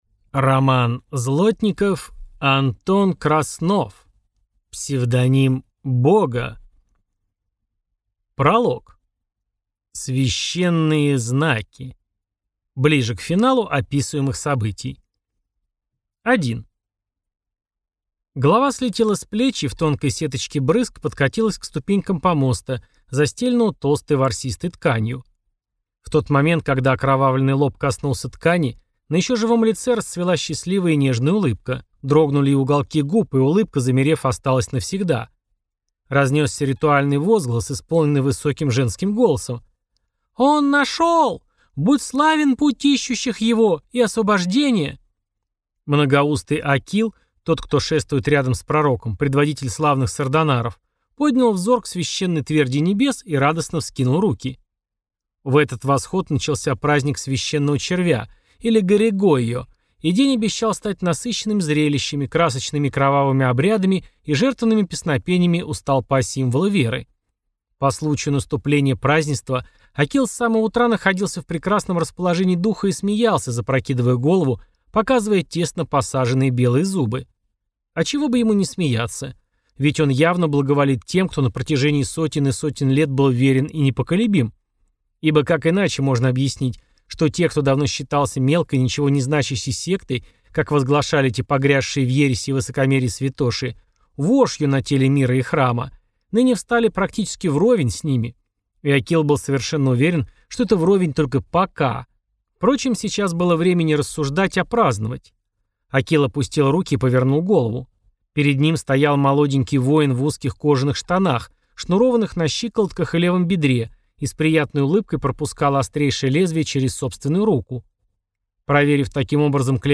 Аудиокнига Псевдоним бога | Библиотека аудиокниг